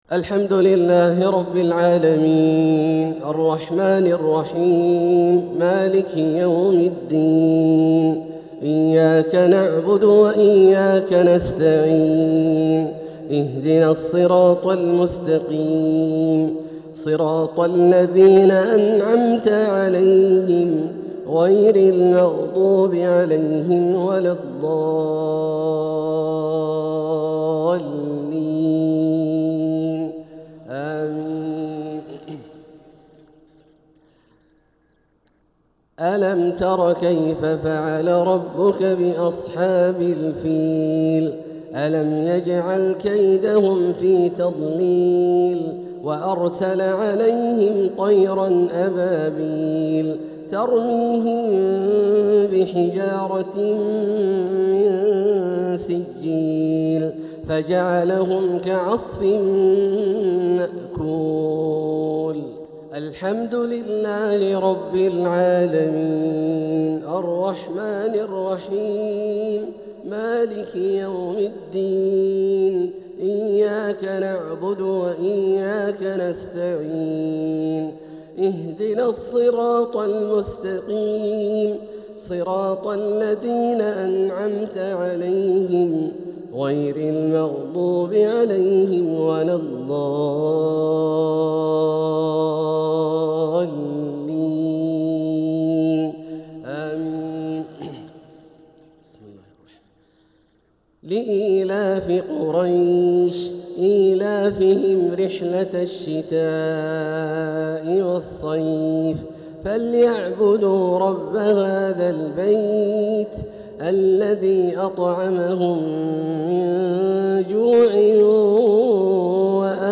تلاوة لسورتي الفيل و قريش | صلاة الجمعة 3-9-1447هـ > ١٤٤٧هـ > الفروض - تلاوات عبدالله الجهني